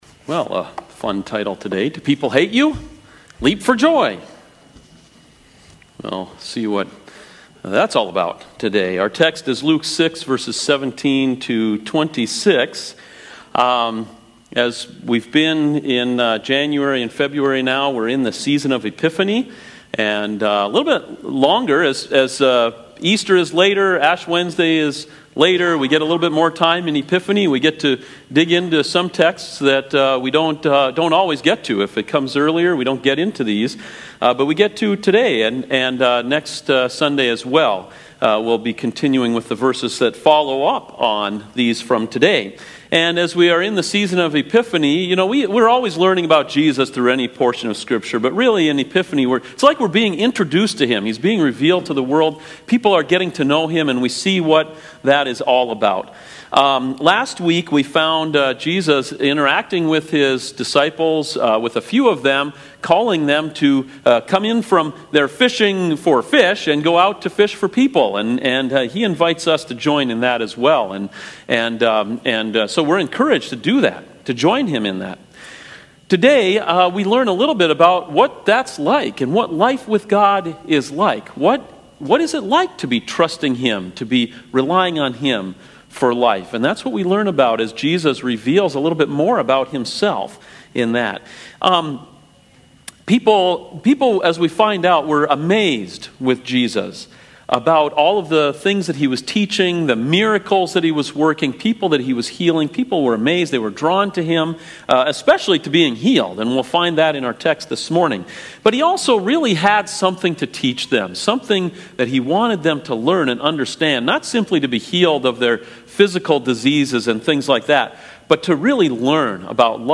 CoJ Sermons Do People Hate You? Leap for Joy! (Luke 6:17-26)